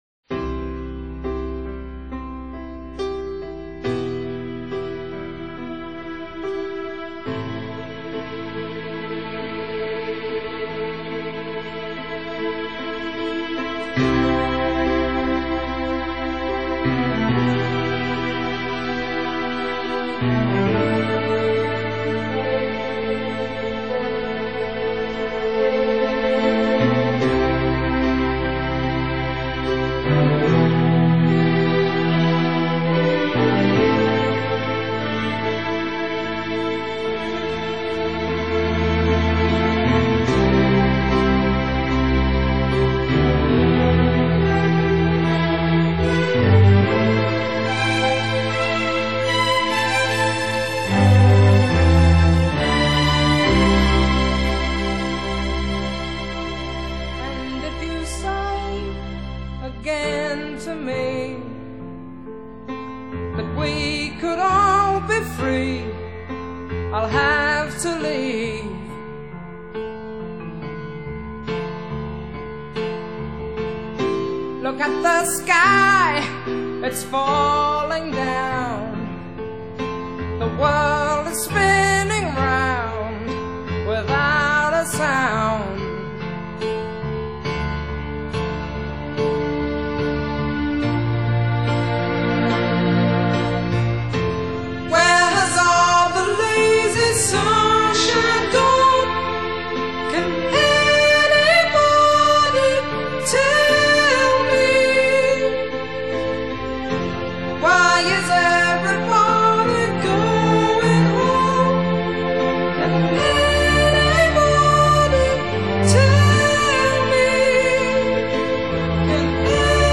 Pop | MP3 CBR 320 Kbps | 165+162+147 MB | LQ Cover |